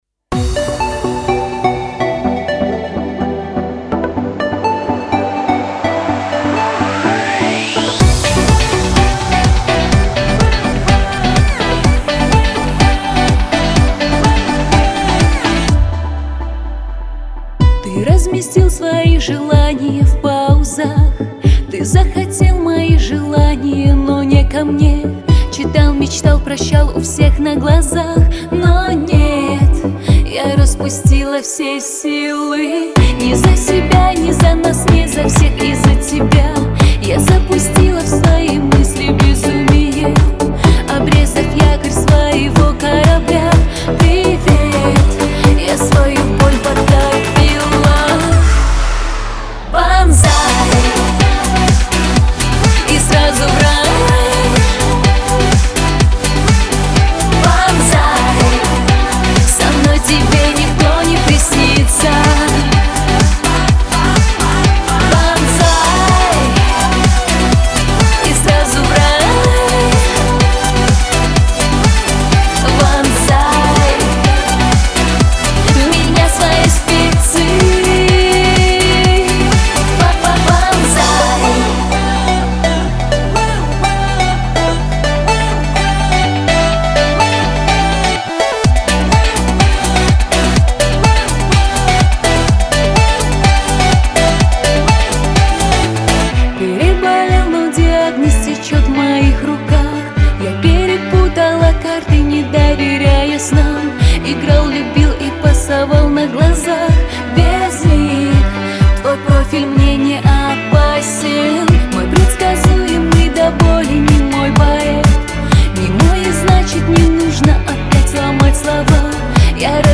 История такая: Пришел Артист. попросил на вокал сделать попсовую аранжировку .Я сделал. На Tophit получил трек рейтинг: 0.2. Вот я и пытаюсь понять, пробелма в аранжировке, сведении или вокале и тексте.